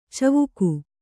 ♪ cavuku